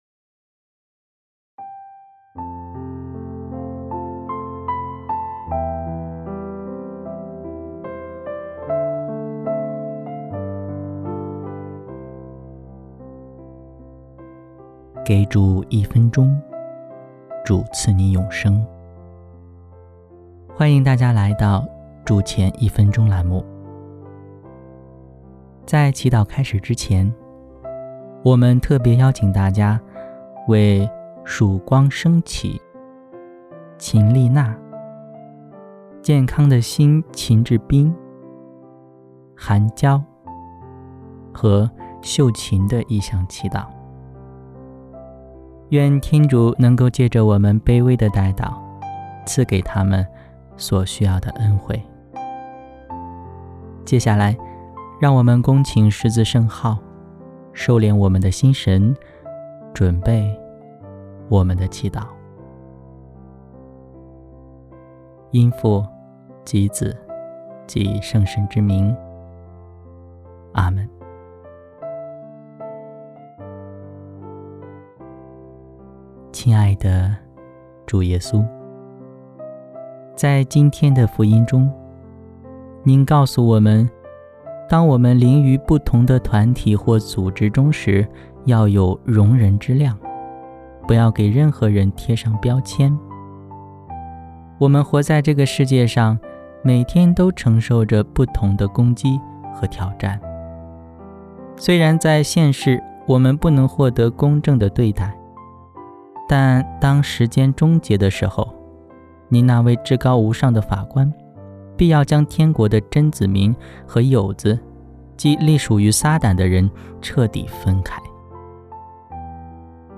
祷词：